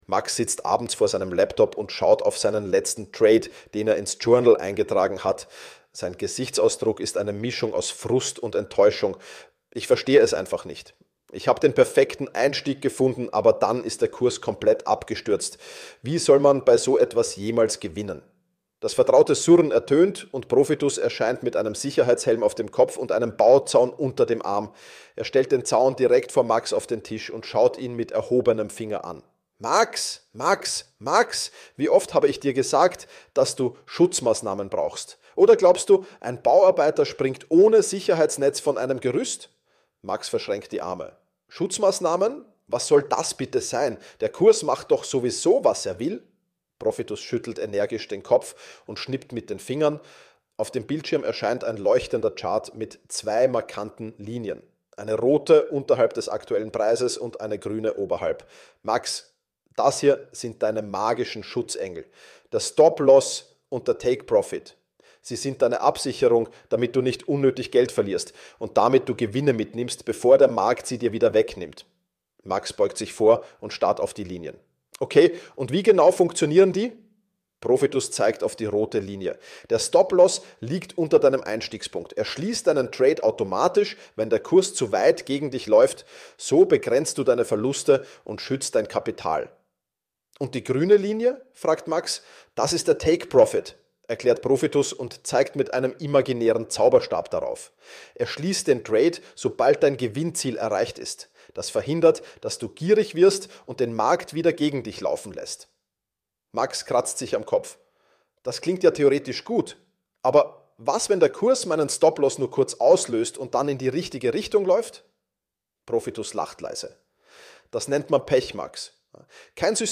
Meine Stimme wurde dafür geklont.